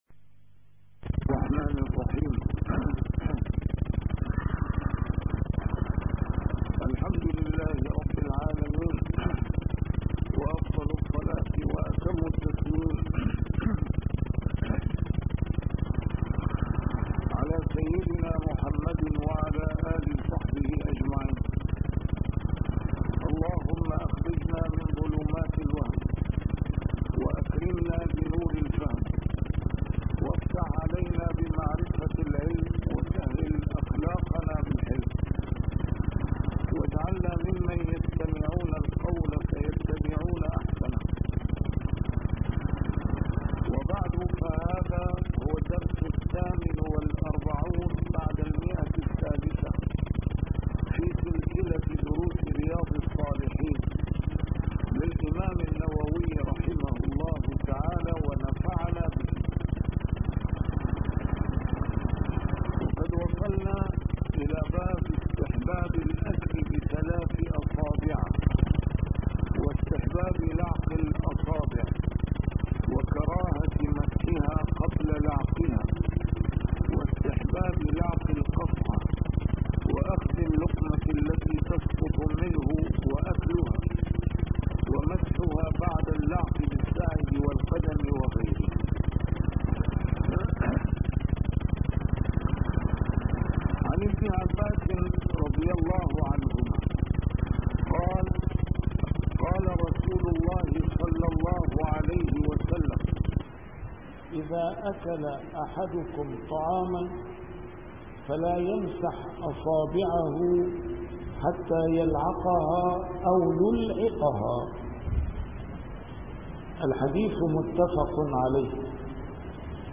A MARTYR SCHOLAR: IMAM MUHAMMAD SAEED RAMADAN AL-BOUTI - الدروس العلمية - شرح كتاب رياض الصالحين - 648- شرح رياض الصالحين: الأكل بثلاث أصابع